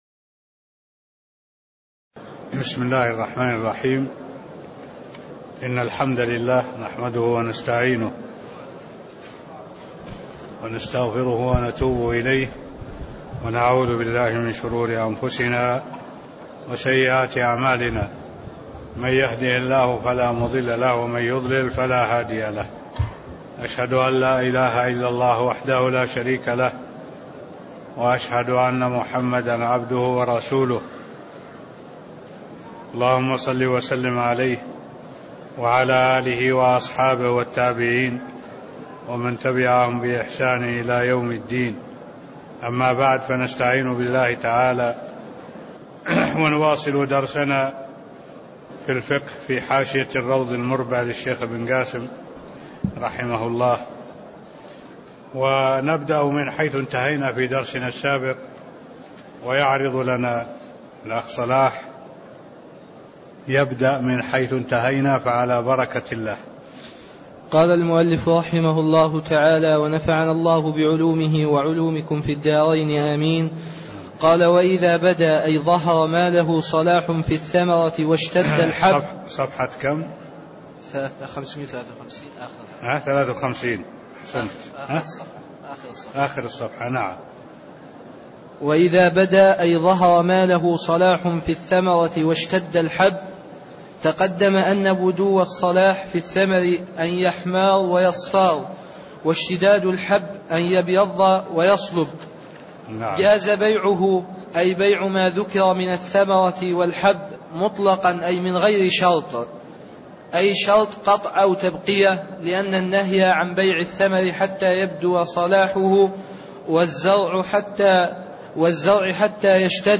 المكان: المسجد النبوي الشيخ: معالي الشيخ الدكتور صالح بن عبد الله العبود معالي الشيخ الدكتور صالح بن عبد الله العبود فصل قوله: (واذا بدأ أي ظهر ماله صلاح في الثمرة) ص553 (04)